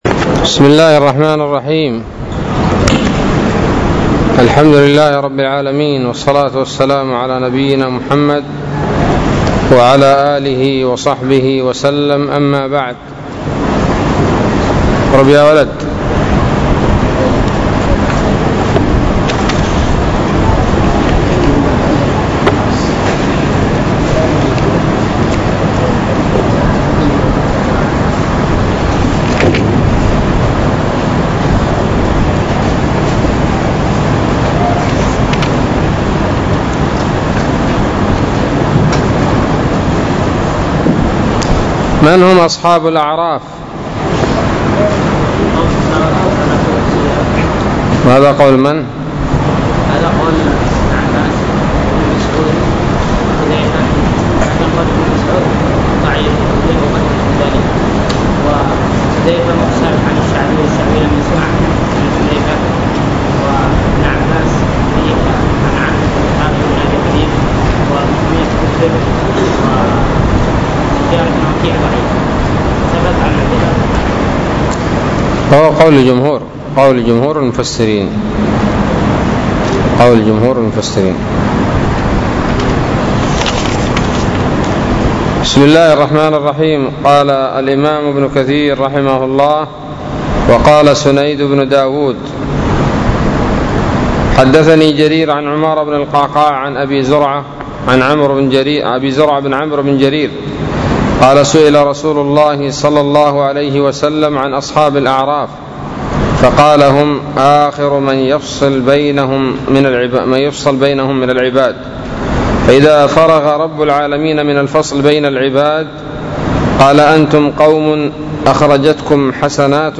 الدرس العشرون من سورة الأعراف من تفسير ابن كثير رحمه الله تعالى